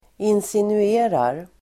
Ladda ner uttalet
Uttal: [insinu'e:rar]
insinuerar.mp3